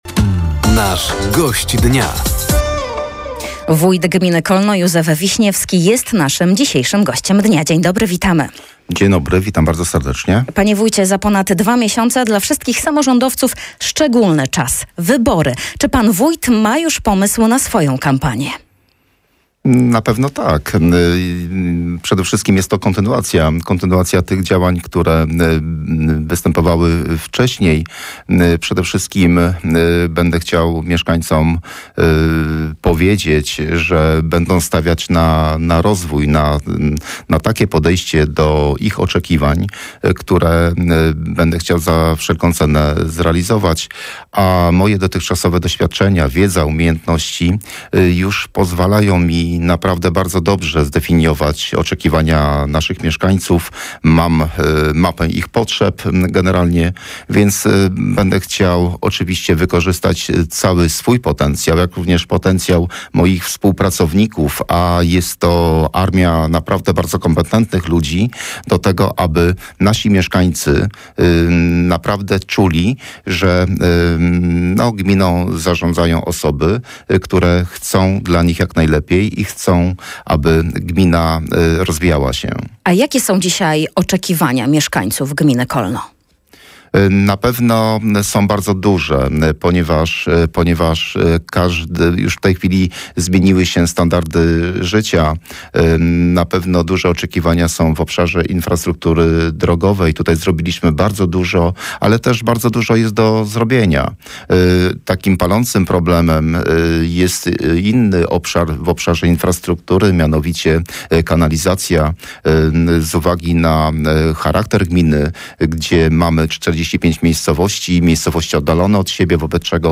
Gościem Dnia Radia Nadzieja był wójt gminy Kolno, Józef Wiśniewski. Tematem rozmowy był start w nadchodzących kwietniowych wyborach samorządowych, a także rozpoczęte kluczowe inwestycje na terenie gminy Kolno.